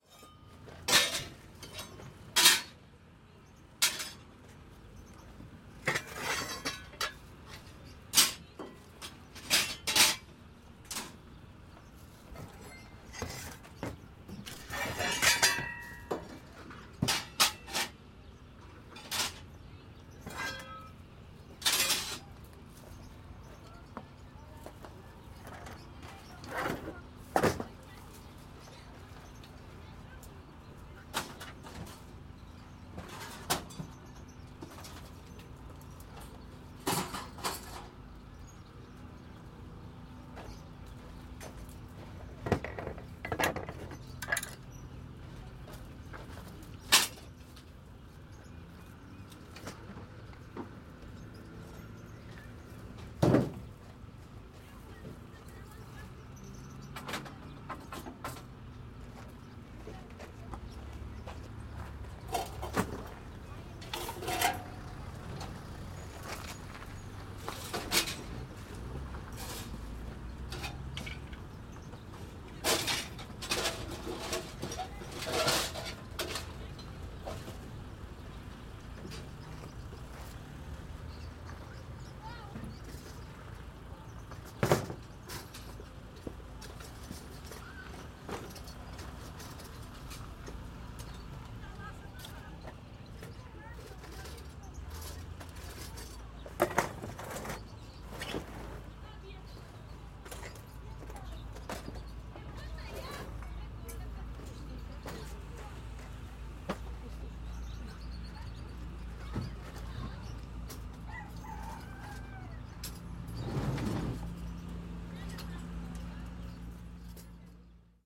Na feira semanal das terças-feiras um casal de vendedores de ferramentas e alfaias desmonta a tenda de venda. Gravado com Edirol R44 e um par de microfones de lapela Audio-Technica AT899.
Tipo de Prática: Paisagem Sonora Rural
Viseu-Largo-da-Feira-Vendedores-de-ferramentas-e-alfaias-desmontam-tenda.mp3